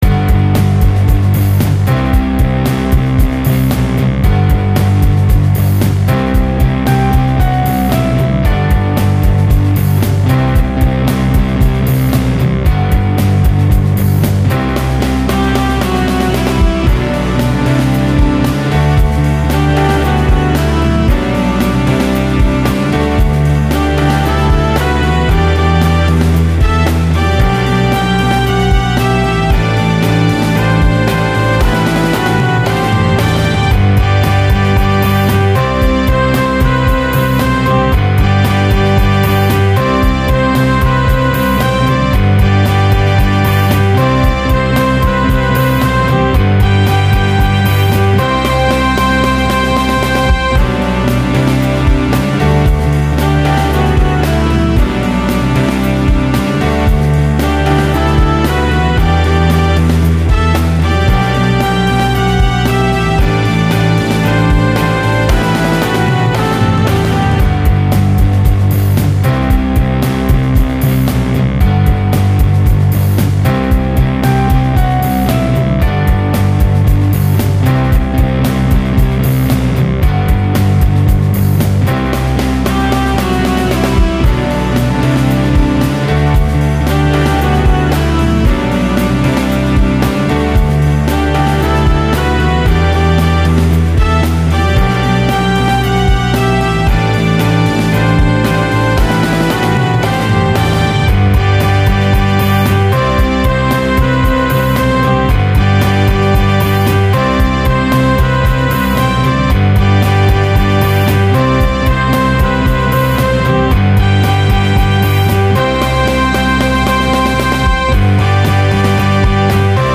はい、つーわけでパワーコードを使った曲を作ってみた。
底を作るだけだから、今回でいうとピアノみたいに実際にコードが判明するような伴奏がなければ下手したら音楽としては長調なのか短調なのかよくわからない音楽になってしまいそう。